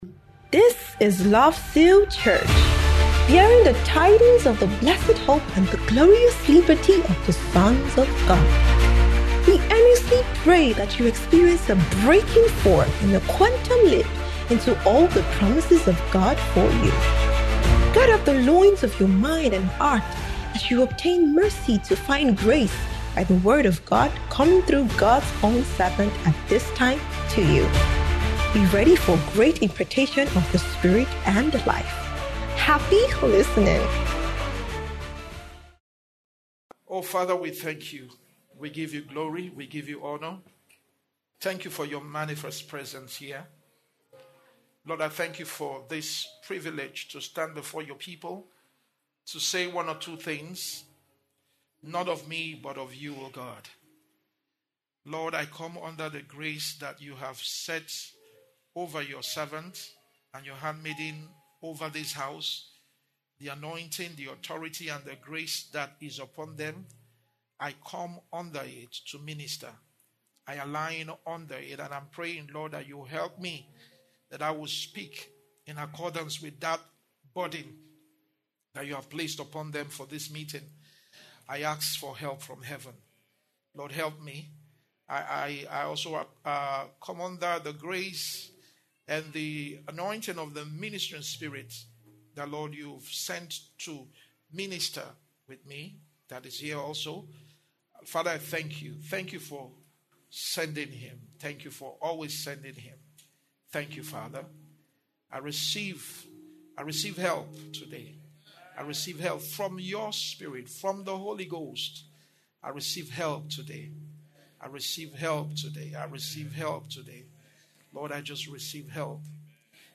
Sermons
Spirit Life Reign 2025 - Day 4